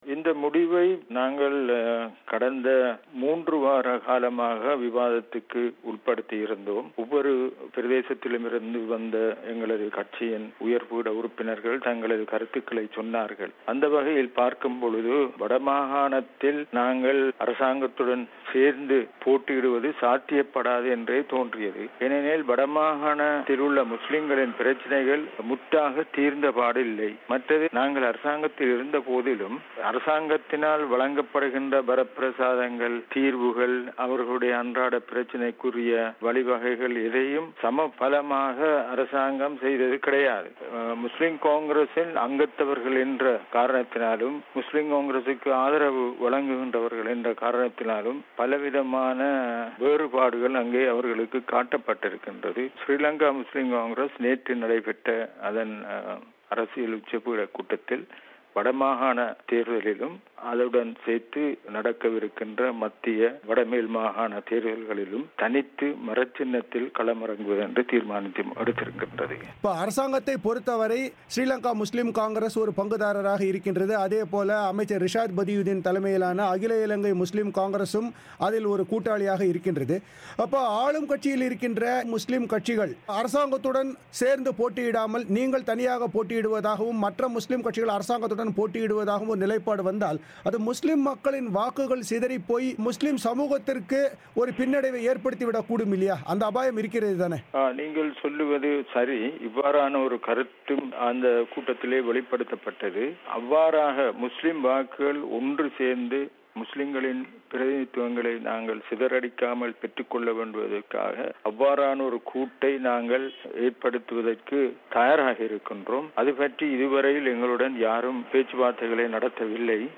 இலங்கையின் வடக்கு உட்பட மூன்று மாகண சபைகளுக்கு நடைபெறவுள்ள தேர்தல்களில் ஸ்ரீலங்கா முஸ்லிம் காங்கிரஸ் தனித்து போடியிடுவது ஏன் என்பது குறித்து அக்கட்சியின் பொதுச் செயலர் ஹஸன் அலி தமிழோசைக்கு அளித்த பேட்டி